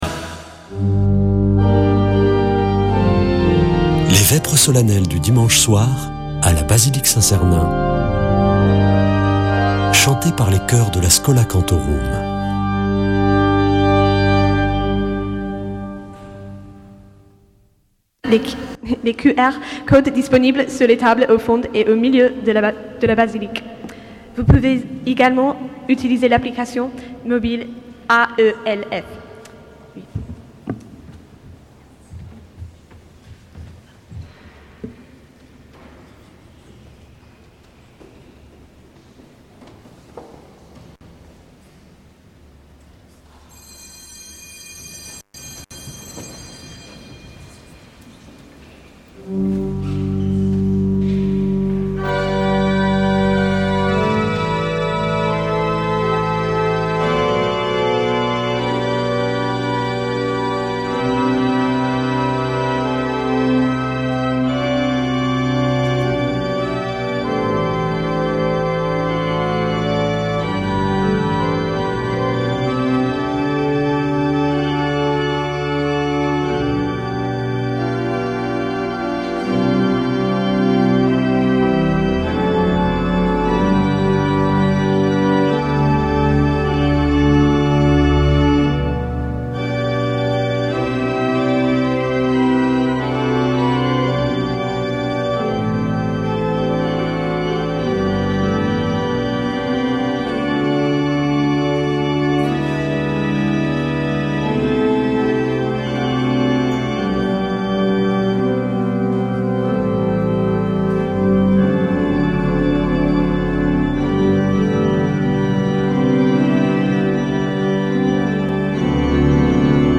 Accueil \ Emissions \ Foi \ Prière et Célébration \ Vêpres de Saint Sernin \ Vêpres de Saint Sernin du 05 avr.
Une émission présentée par Schola Saint Sernin Chanteurs